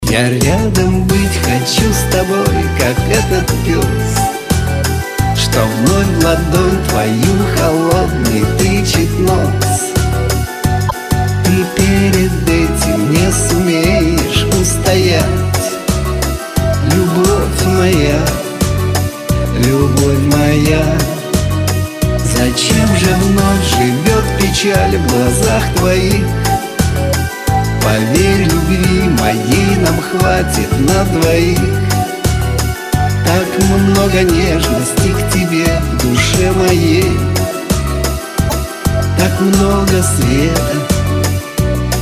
из Шансон